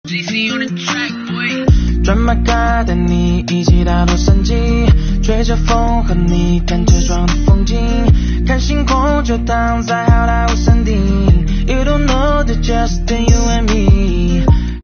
是群众透过猫眼拍摄的